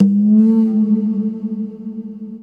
PERQ FX   -R.wav